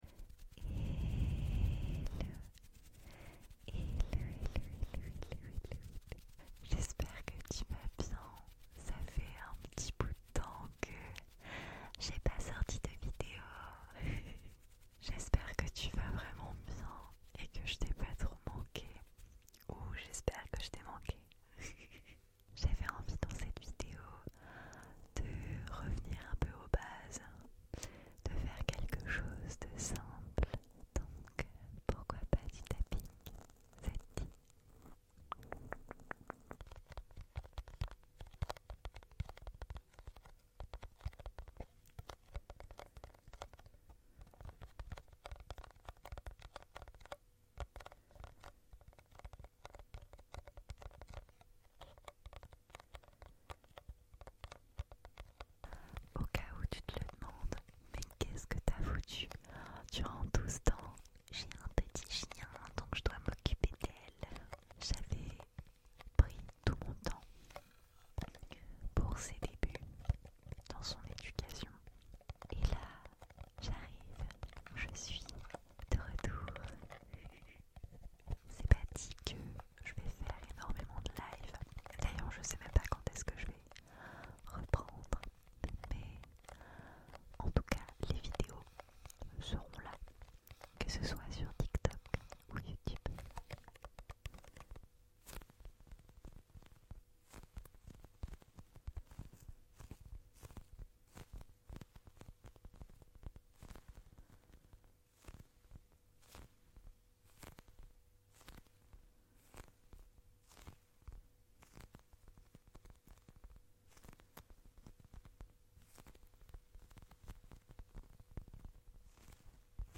Après deux mois d’absence, je suis enfin de retour 🫶🏻 L’éducation canine, c’est génial… mais épuisant ! J’espère que vous ne m’en voulez pas trop 🤍 Je reviens en douceur, comme on aime : simplicité et tapping pour chouchouter tes oreilles 🥰✨ Installe-toi bien, détends-toi, et profite…